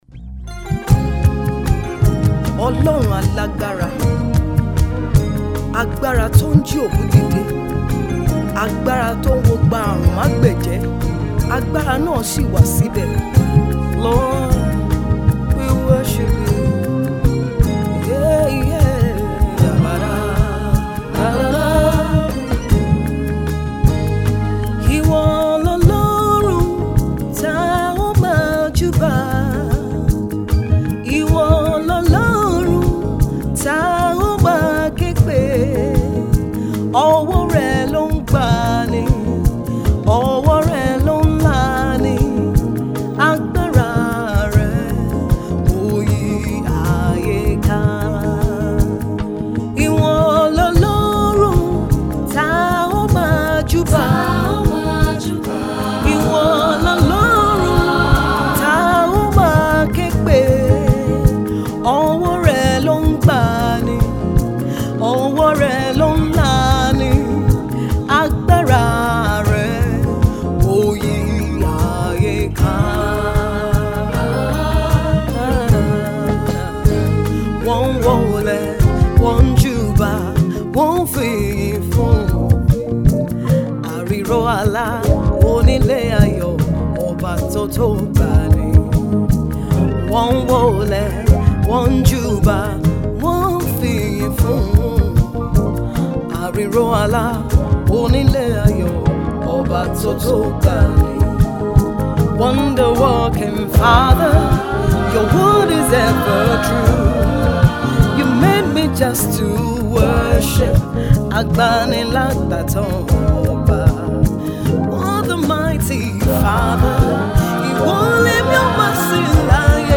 gospel
The Queen of Trado-Contemporary
spiritual soothing rendition